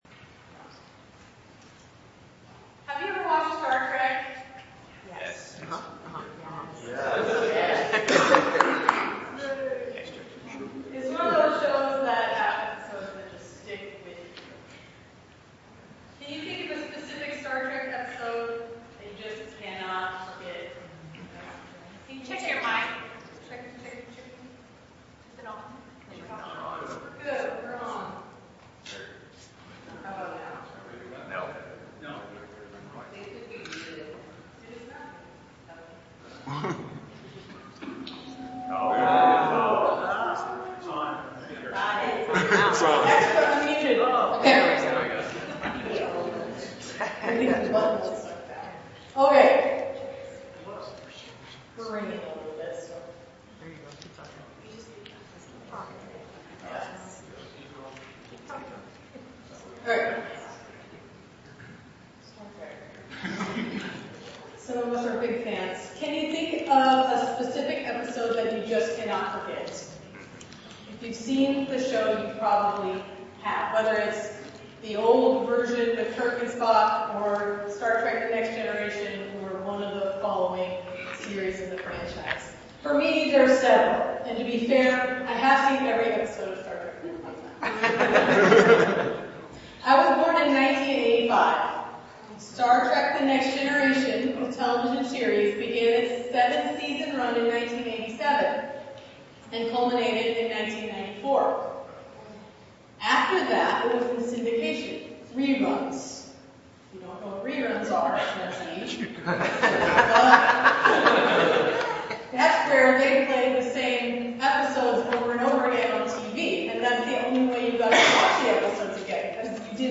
Find Belonging in God’s Alternate Reality: A Star Trek Sermon – East County Church of Christ
Service Type: 10:30 Hour - Sermon This sermon uses Star Trek: The Next Generation's "The Measure of a Man" episode to illustrate the idea that true belonging is more than just being welcome; it's about entering a new reality, which Jesus called "God's Kingdom."